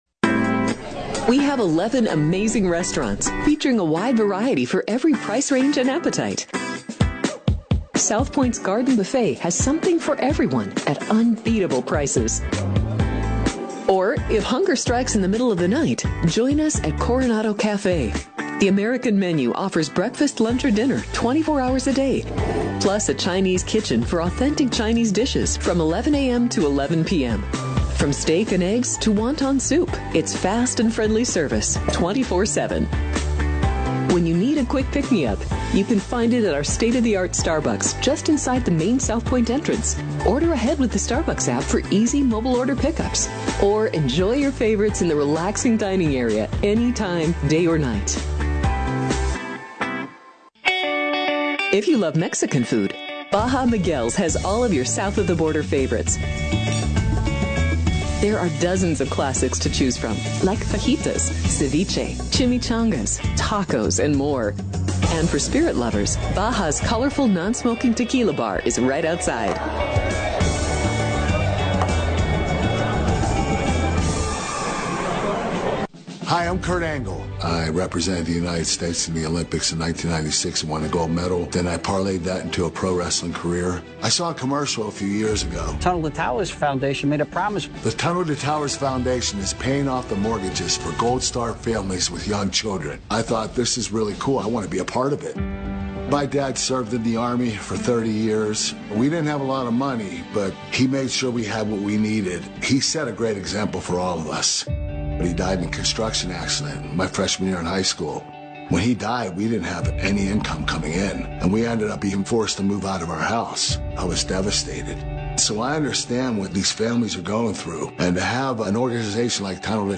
Thoroughbred Racing News, Previews and Selections, broadcast from Las Vegas, Nevada